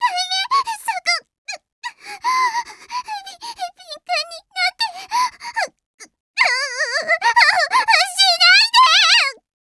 ひかりサンプルボイス1